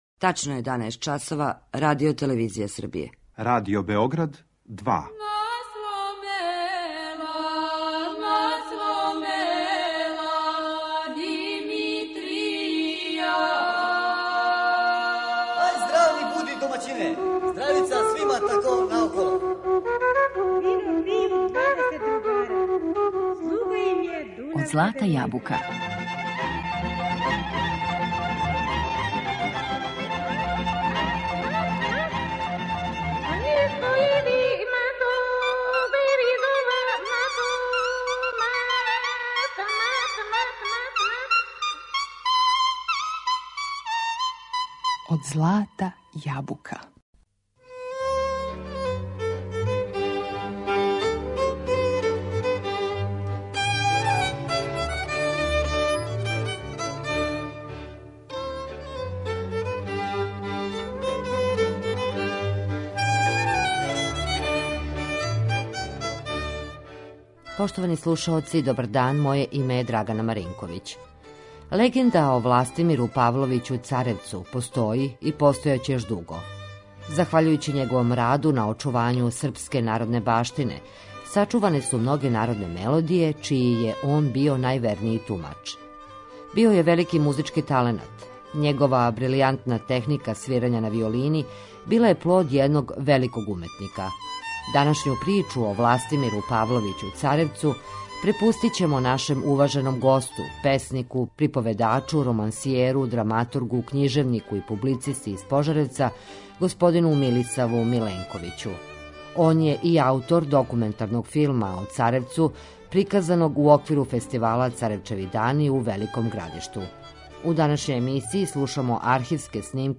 Архивски снимци народних кола и игара које ћемо емитовати у емисији представљају право богатство у етномузиколошким записима наше музичке традиције.